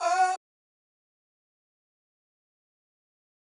VOX [DRIVE THE BOAT].wav